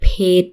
_ pedd